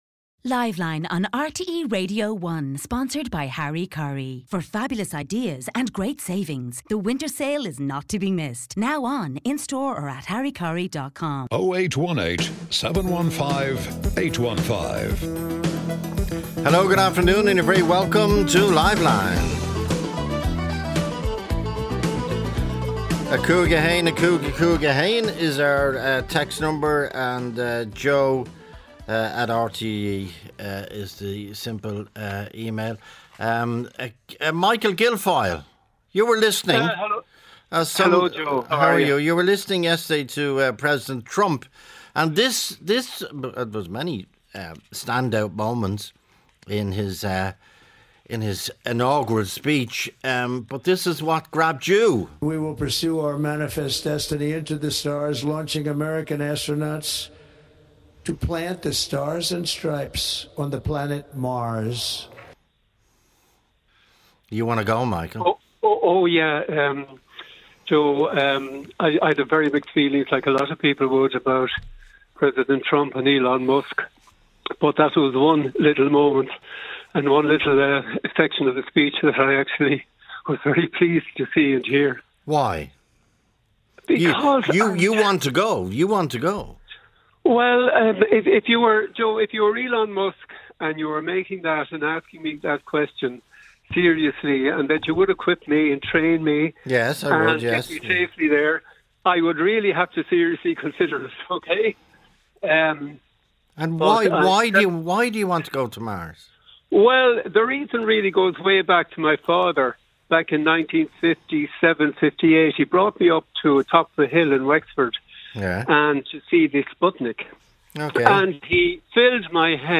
Joe Duffy talks to the Irish public about affairs of the day.